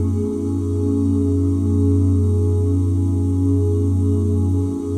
OOHF#SUS13.wav